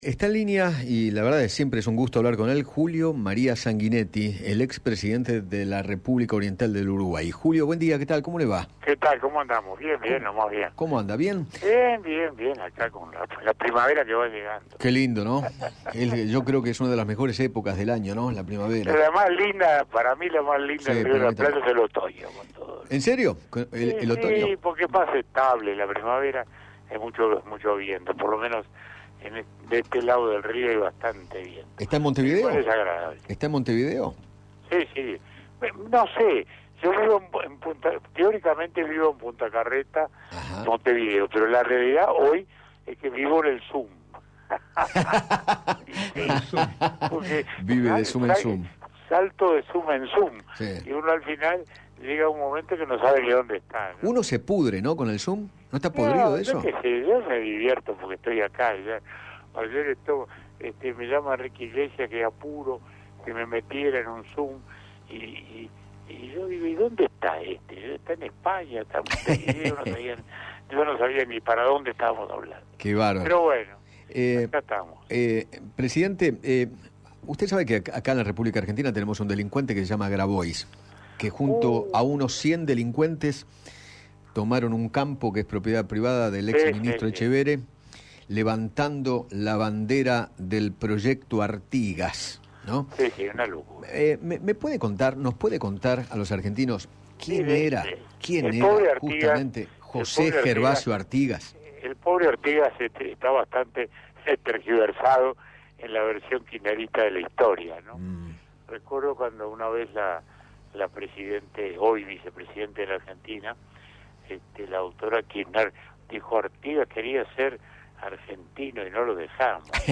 José Maria Sanguinetti, ex Presidente de Uruguay, dialogó con Eduardo Feinmann sobre el apoyo al Proyecto Artigas que se dio ayer en Entre Ríos y en la Ciudad de Buenos Aires, en el marco de la disputa que mantiene la familia Etchevehere, y contó quién fue José Gervasio Artigas.